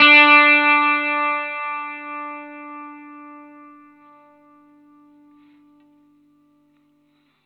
R12NOTE D +.wav